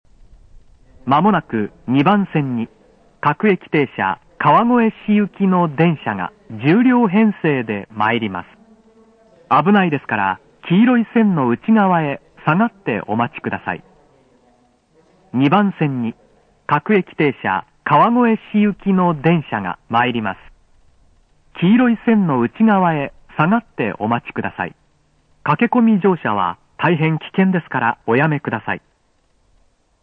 接近用と発車用とではスピーカーが大半異なっていますが、バスレフとの相性は悪く音割れ気味です。
＜スピーカー＞ 接近放送用：TOAバスレフ白　発車メロディー用：TOA小
接近放送・男性（普通・川越市）